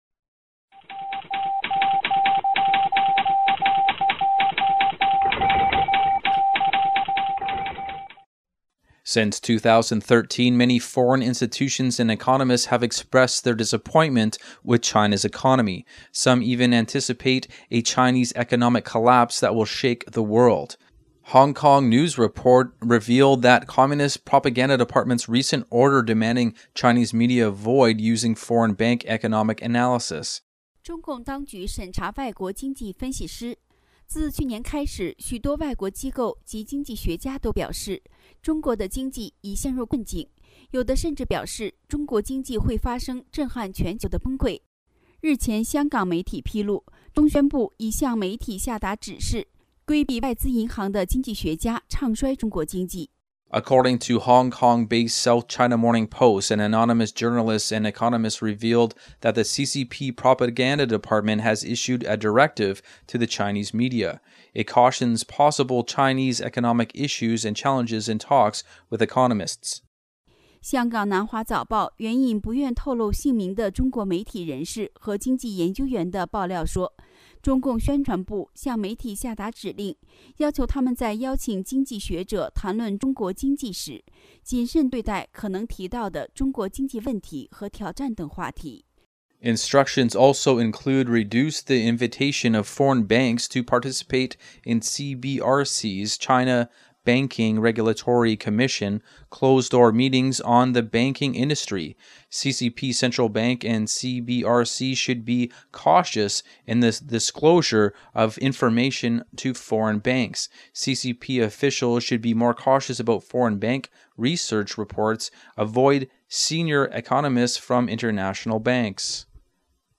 Type: News Reports
128kbps Mono